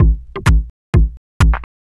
Electrohouse Loop 128 BPM (27).wav